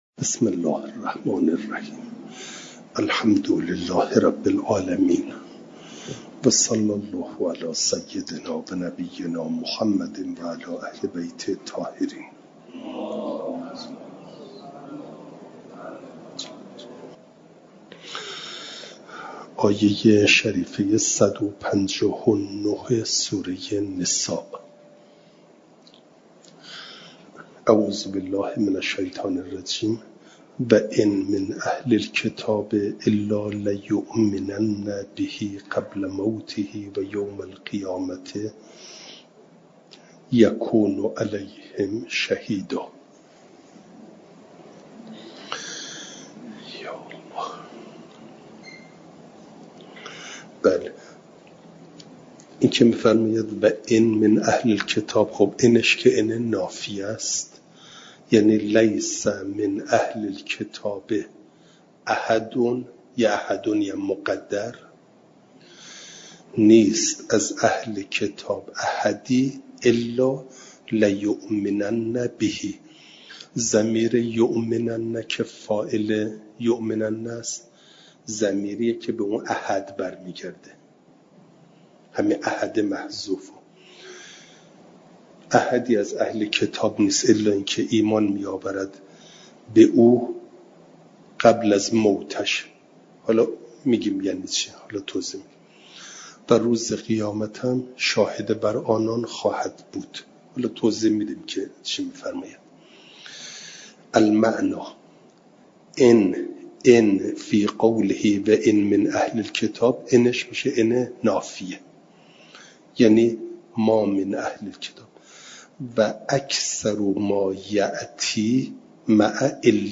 جلسه چهارصد و پنجم درس تفسیر مجمع البیان